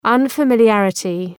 Προφορά
{,ʌnfə,mıl’jærətı}